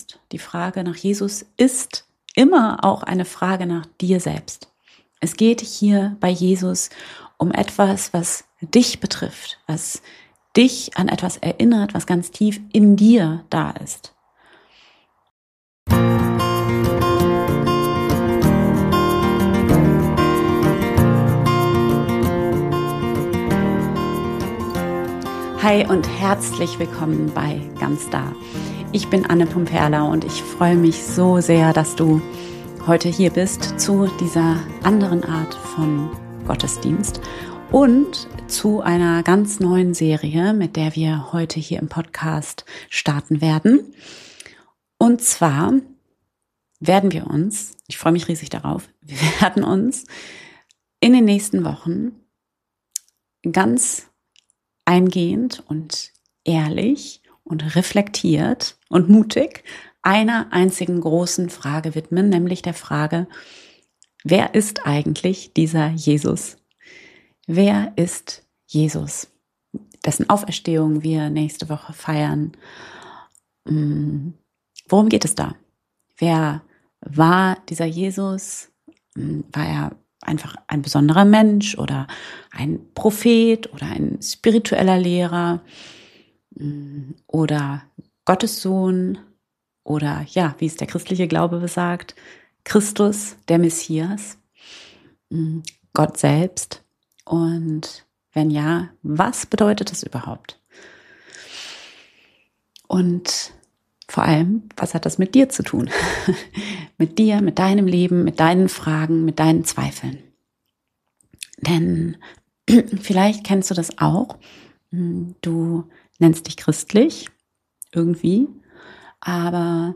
Und am Ende machen wir gemeinsam eine kurze Meditation, die all das in die Erfahrung holt.